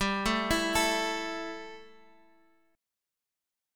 A5/G chord